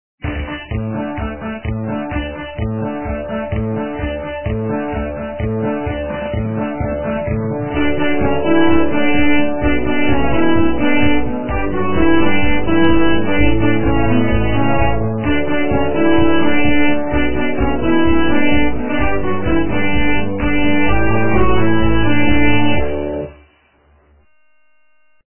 - русская эстрада